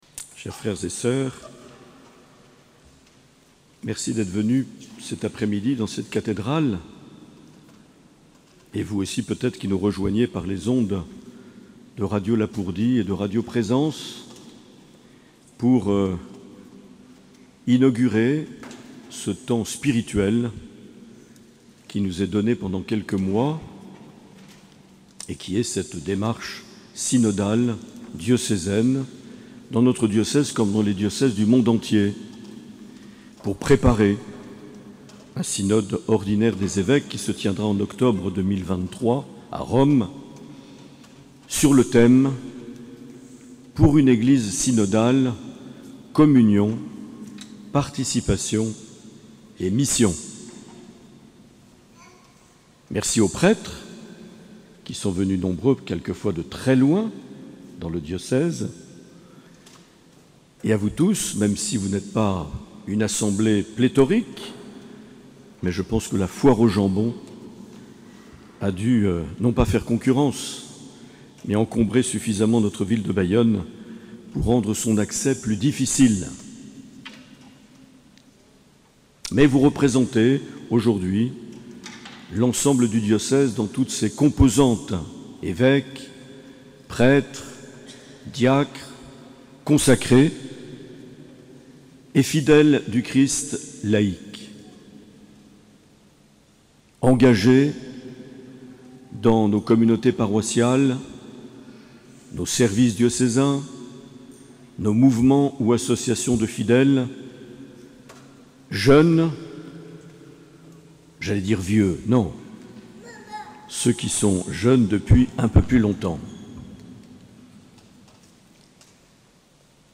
17 octobre 2021 - Cathédrale de Bayonne - Messe d’ouverture du Synode
Accueil \ Emissions \ Vie de l’Eglise \ Evêque \ Les Homélies \ 17 octobre 2021 - Cathédrale de Bayonne - Messe d’ouverture du (...)
Une émission présentée par Monseigneur Marc Aillet